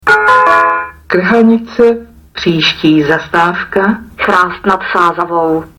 Vzhledem k tomu, že náhradní dopravu zajišťovaly autobusy Dopraního podniku hlavního města Prahy, ve všech spojích (včetně toho do Čerčan) byly vyhlašovány zastávky standardním způsobem.
- Hlášení "Krhanice. Příští zastávka Chrást nad Sázavou" si